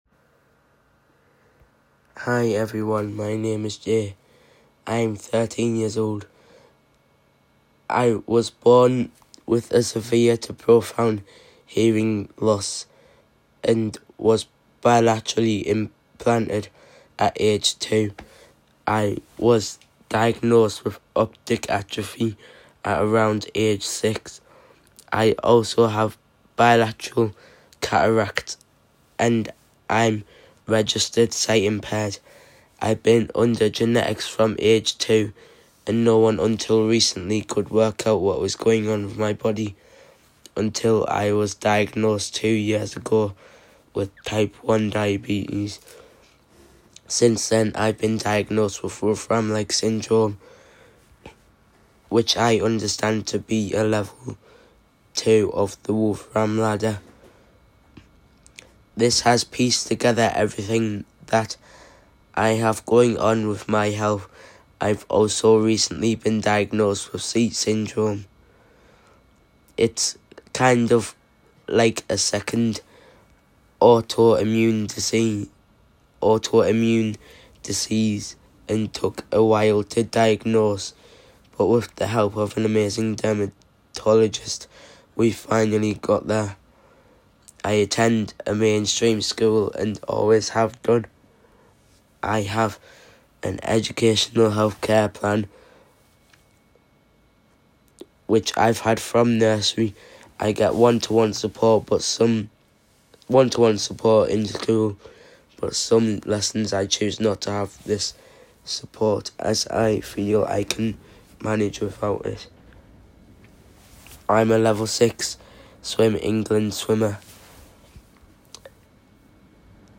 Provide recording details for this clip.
Unfortunately the sound didn’t work during the filming